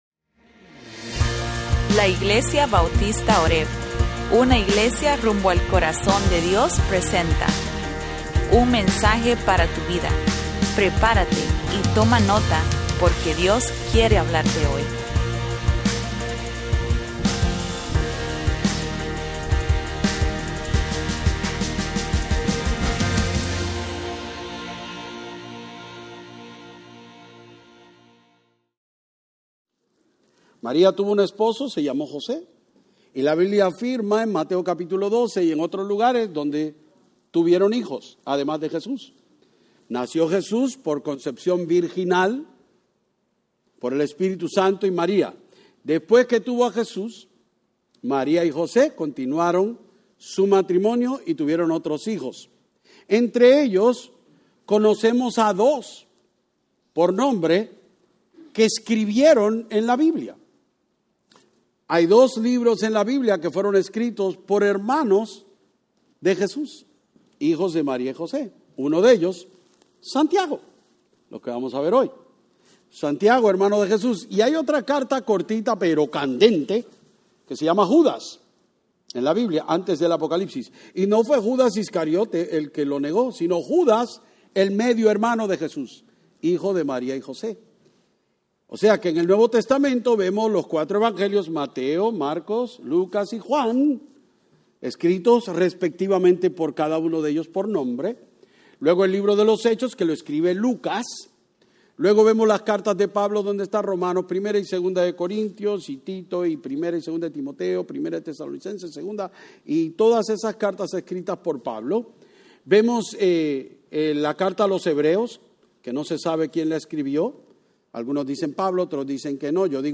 Sermones-3_30_25-ingles.mp3